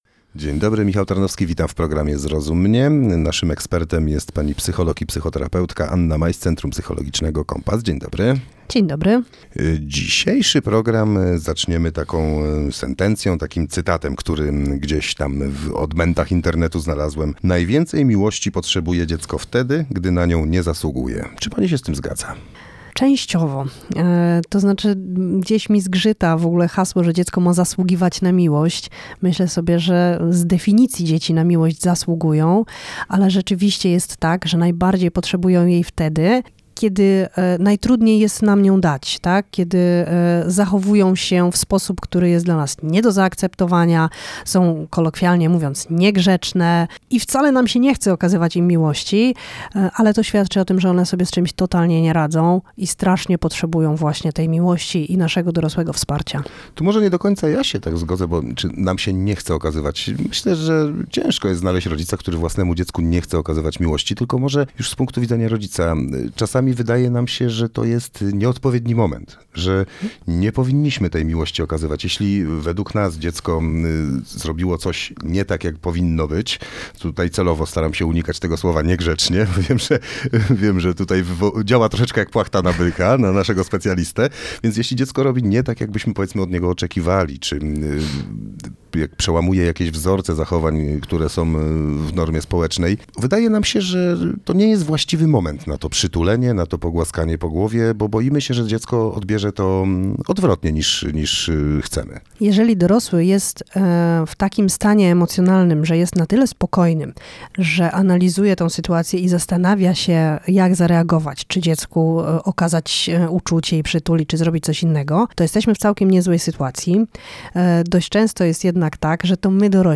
Zrozum mnie – program parentingowy na antenie Radia Radom